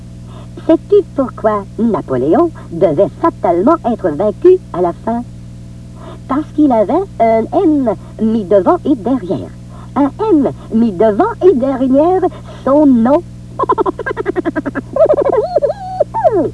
Different voices were used in each country, but all of them kept the original sound effects and of course that distinctive 2-XL laugh!
The Mego 2-XL telling a joke, from the same "Renseignements generaux" program.